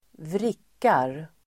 Uttal: [²vr'ik:ar]